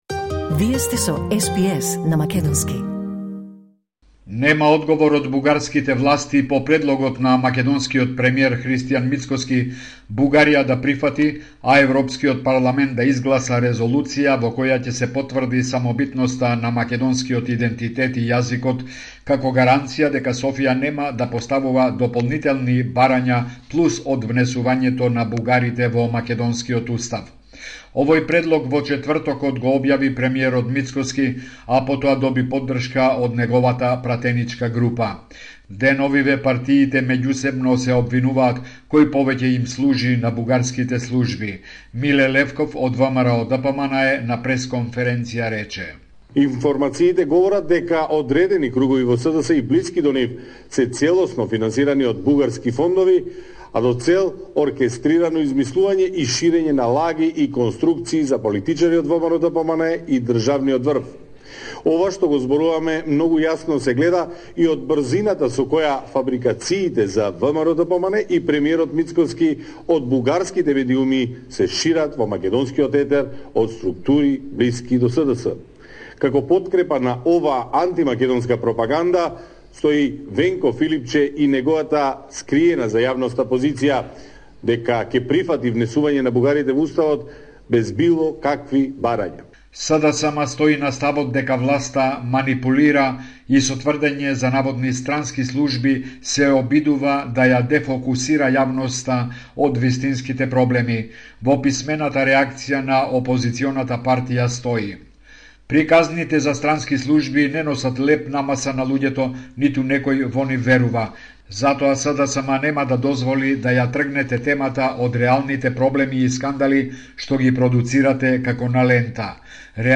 Извештај од Македонија 21 јули 2025